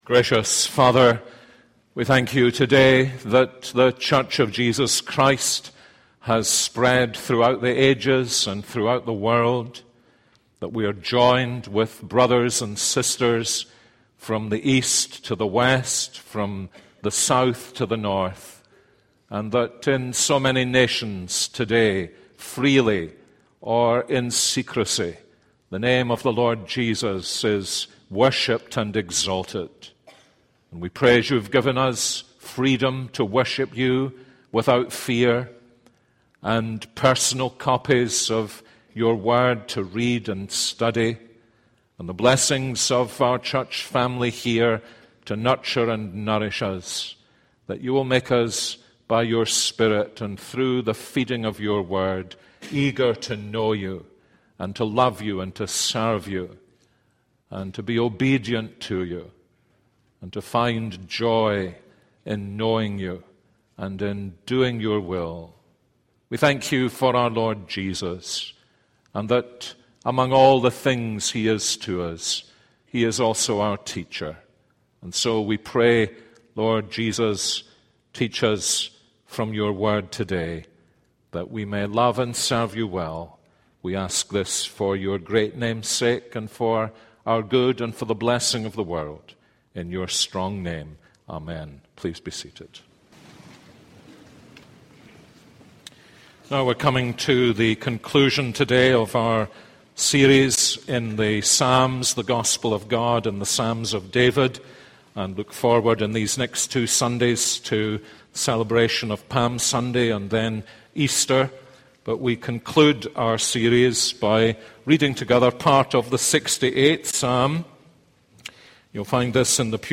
This is a sermon on Psalm 68:1-10.